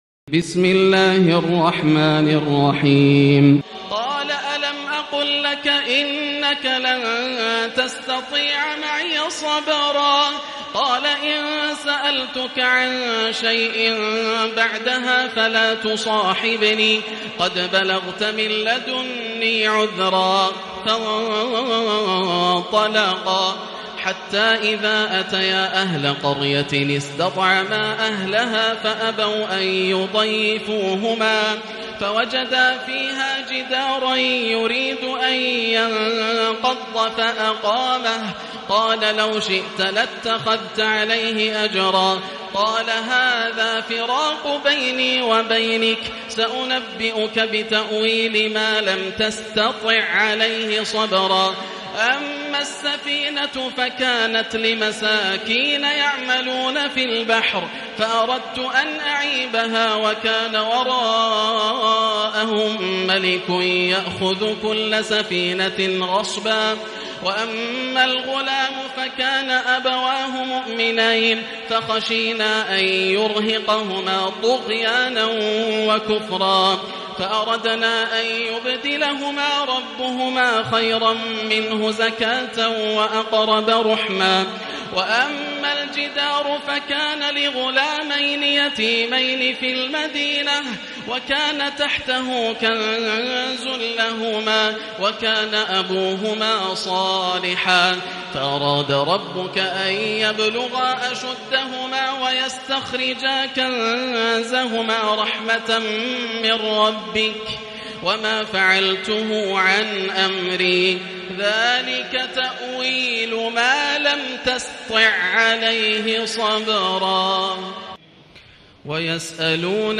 الجزء السادس عشر > مصحف الشيخ ياسر الدوسري (مصحف الأجزاء) > المصحف - تلاوات ياسر الدوسري